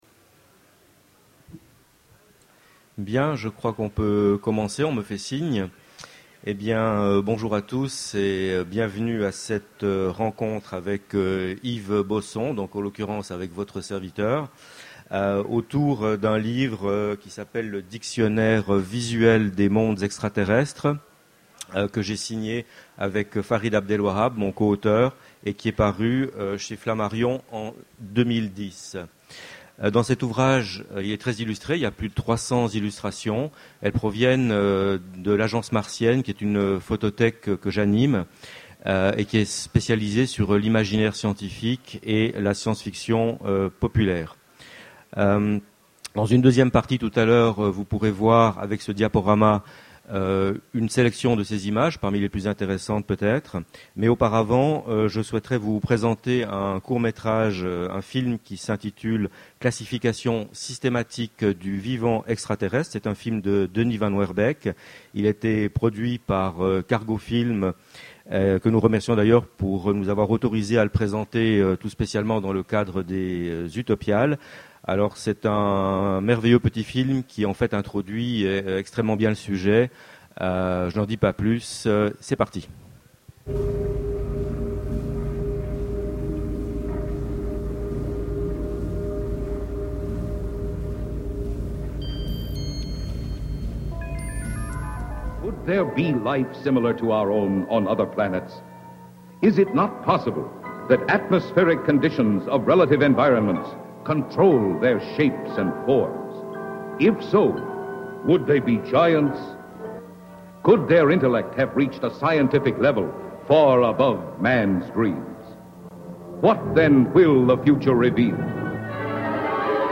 Utopiales 2011 : Conférence